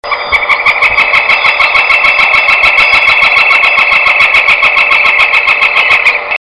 เสียงสกุณา หวานแว่ว แจ้วพงคอน..ราวเว้าวอน ชวนให้ทาย ว่านกใด